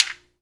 Clap29.wav